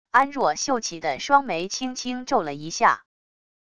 安若秀气的双眉轻轻皱了一下wav音频生成系统WAV Audio Player